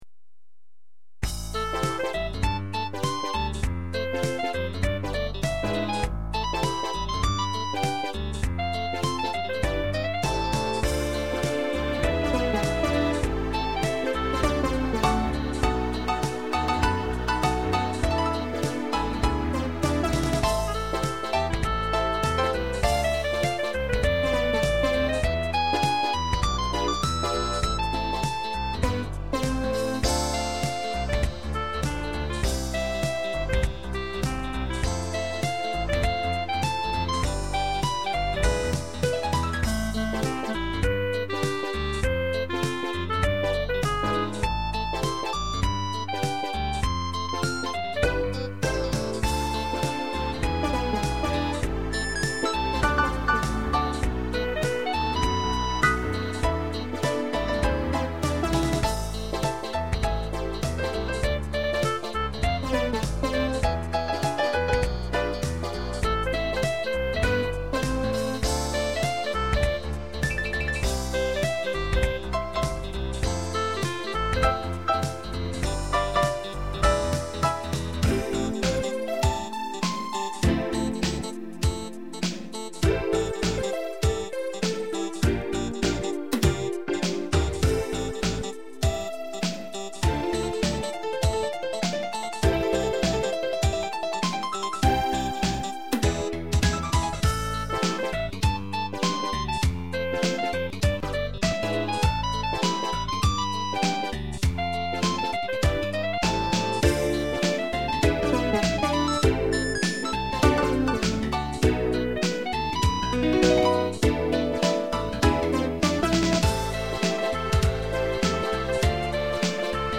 mp3 　アンサンブル 　アレンジデータ使用